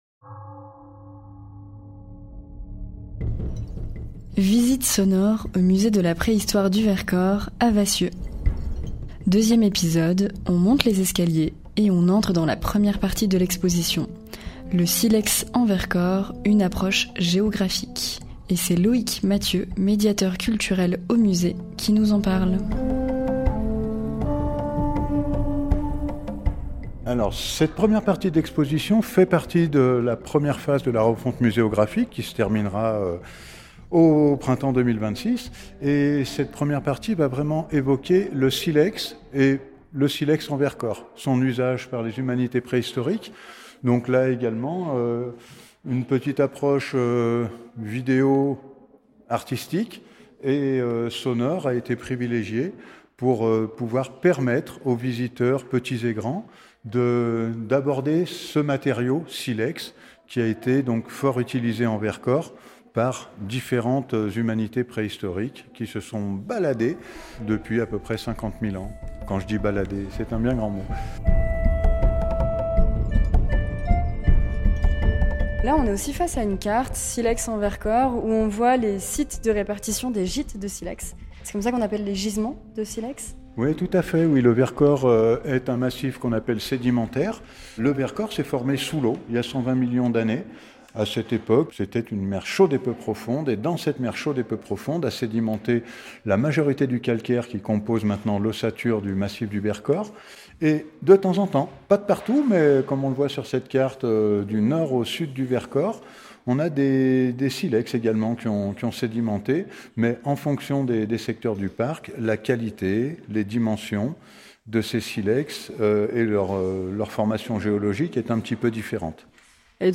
Radio Royans Vercors vous propose une visite sonore du Musée de la Préhistoire du Vercors, à Vassieux.